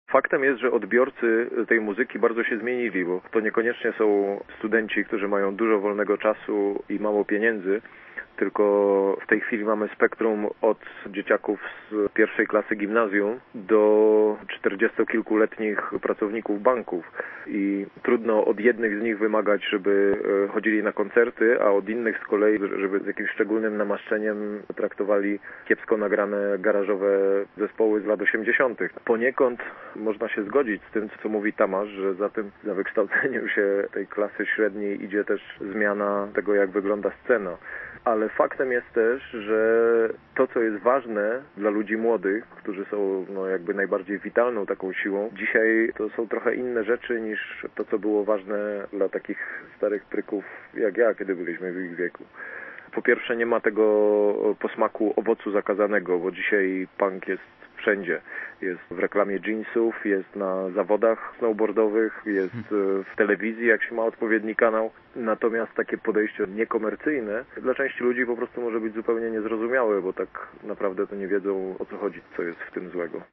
Telefoniczny wywiad